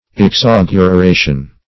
Search Result for " exauguration" : The Collaborative International Dictionary of English v.0.48: Exauguration \Ex*au`gu*ra"tion\, n. [L. exauguratio desecration.] The act of exaugurating; desecration.